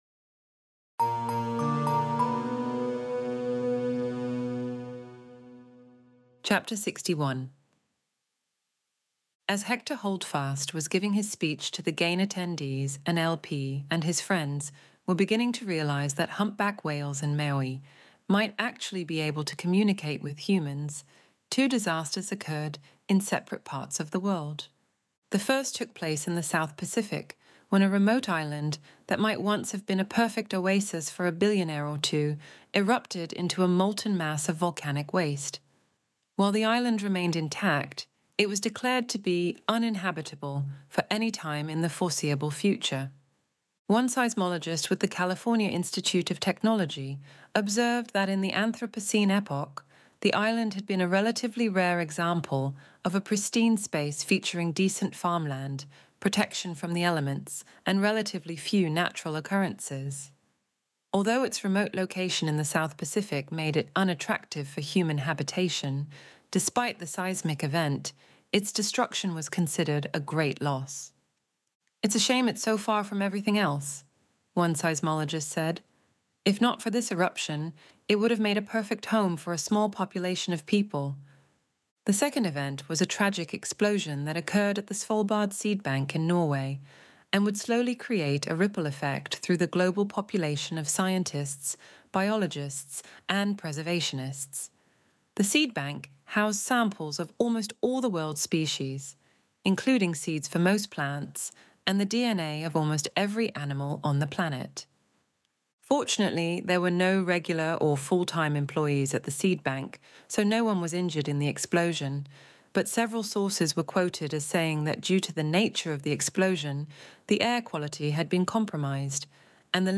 Extinction Event Audiobook